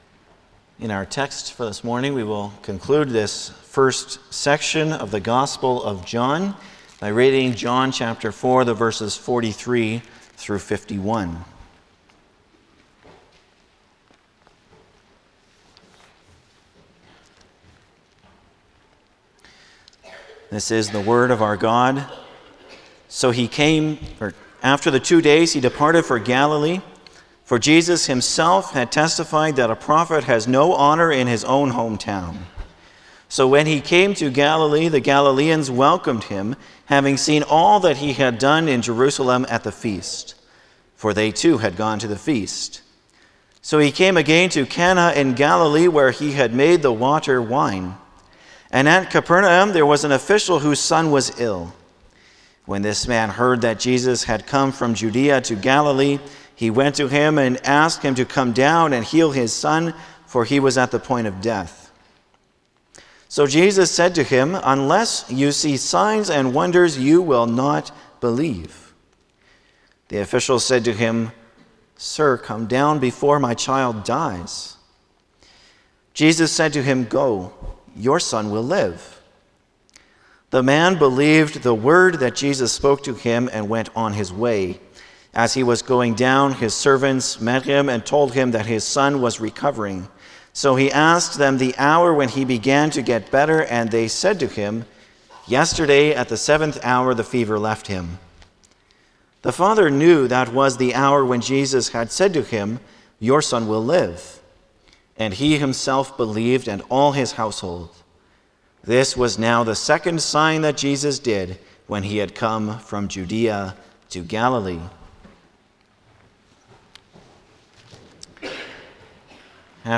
Passage: John 4:43-51 Service Type: Sunday morning
08-Sermon.mp3